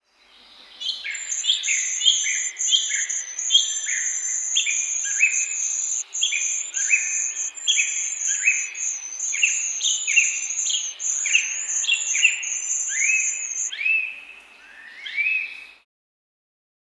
chirping.wav